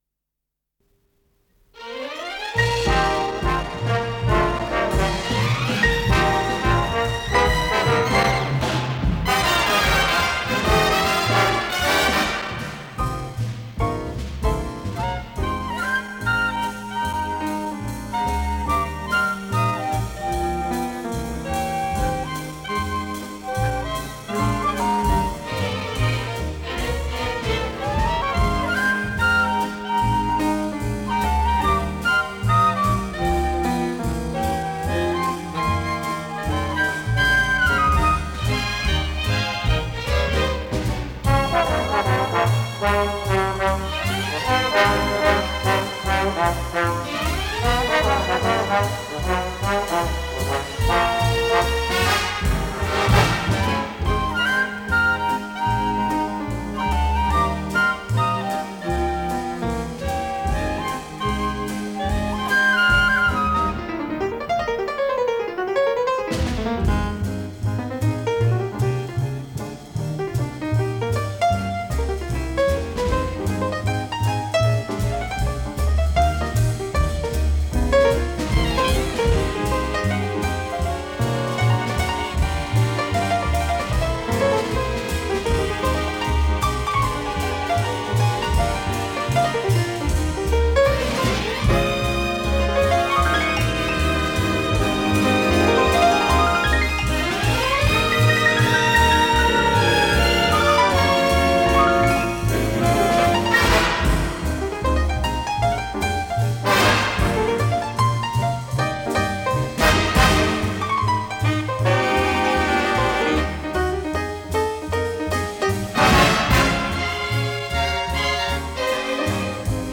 ПодзаголовокПьеса для эстрадного оркестра, ми бемоль мажор
ВариантДубль моно